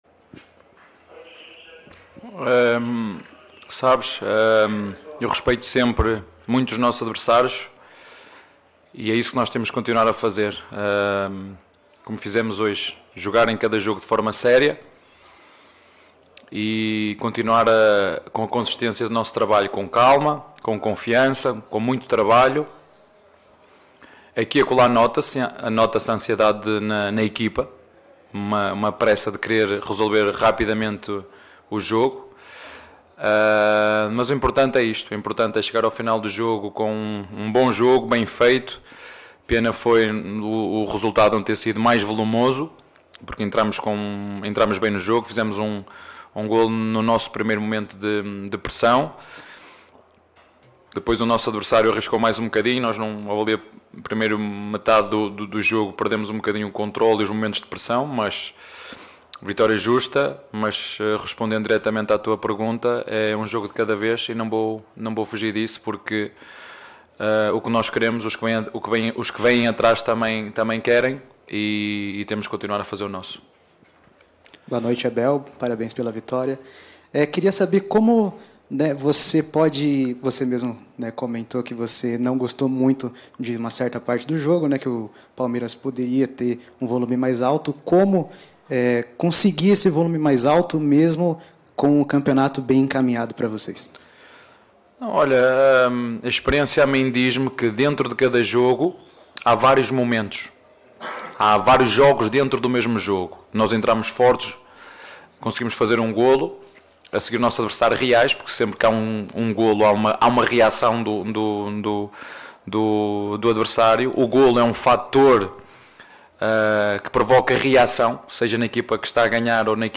COLETIVA-ABEL-FERREIRA-_-PALMEIRAS-X-AVAI-_-BRASILEIRO-2022.mp3